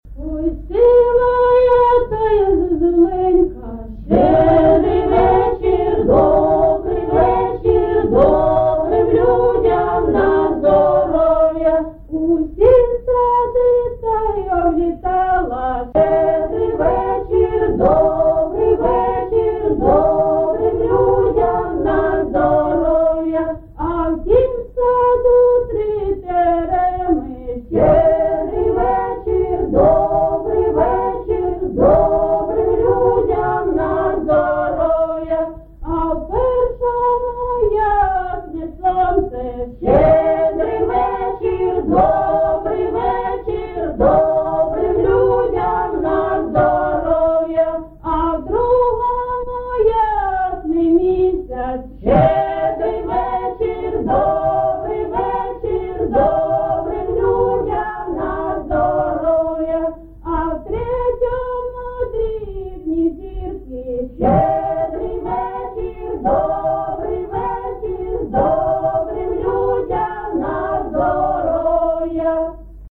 ЖанрЩедрівки
Місце записум. Єнакієве, Горлівський район, Донецька обл., Україна, Слобожанщина